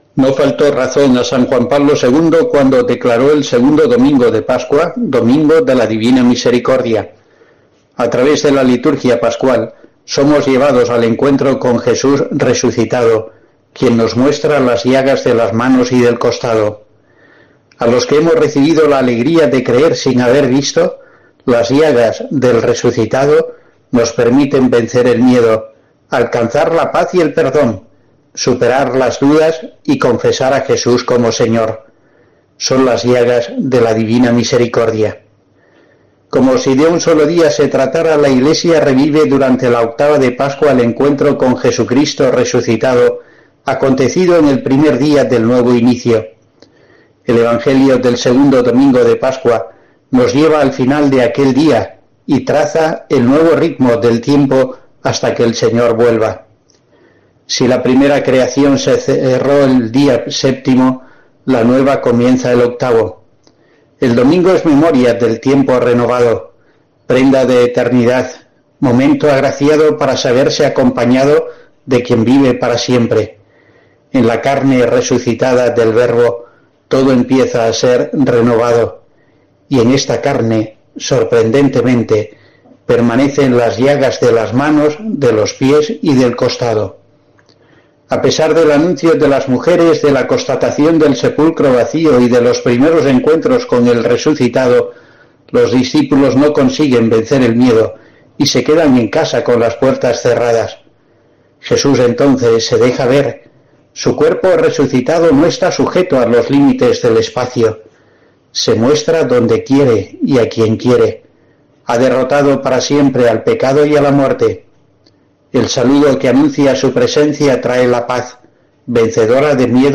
El obispo asidonense recuerda, en su reflexión de esta semana para COPE, que el Segundo Domingo de Pascua es el Domingo de la Divina Misericordia, que instaurara San Juan Pablo II